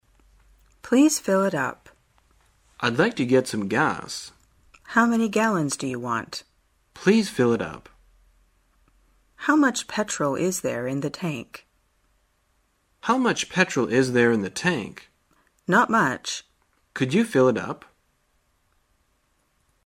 在线英语听力室生活口语天天说 第103期:怎样表达加满油的听力文件下载,《生活口语天天说》栏目将日常生活中最常用到的口语句型进行收集和重点讲解。真人发音配字幕帮助英语爱好者们练习听力并进行口语跟读。